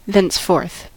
thenceforth: Wikimedia Commons US English Pronunciations
En-us-thenceforth.WAV